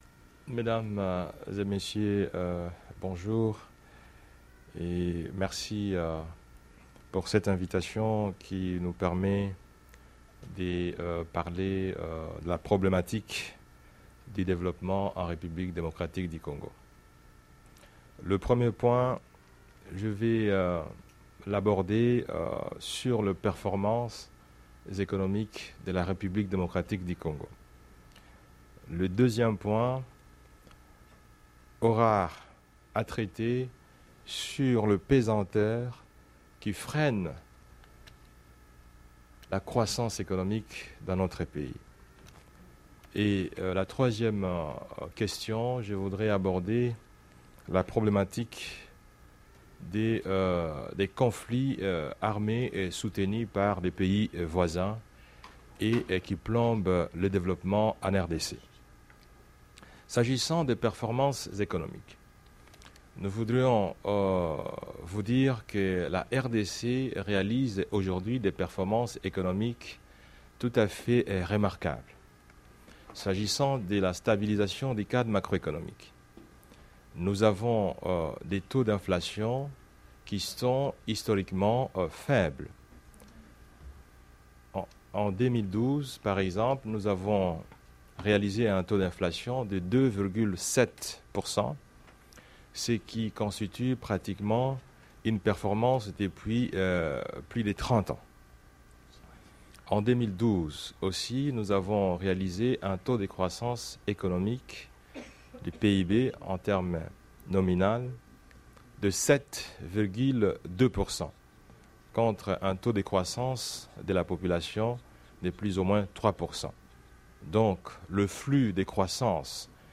Discours de Matata Ponyo